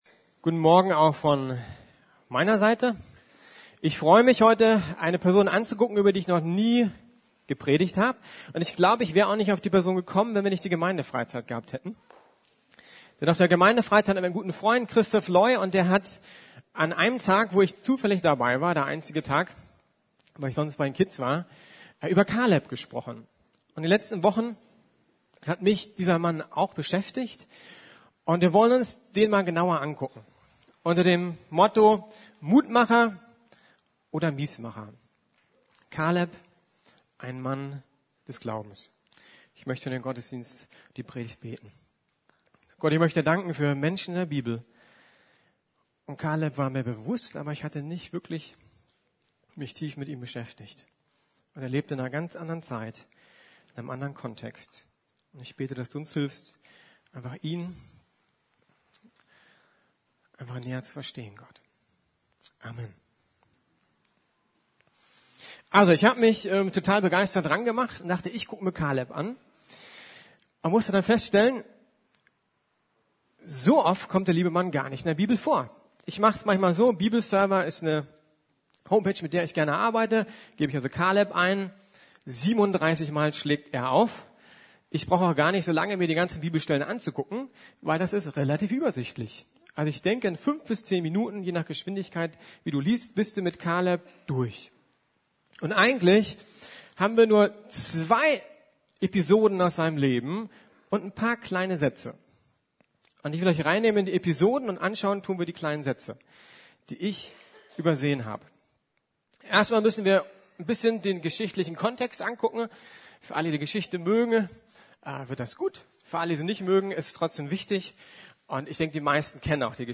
Kaleb - Mutmacher statt Miesmacher ~ Predigten der LUKAS GEMEINDE Podcast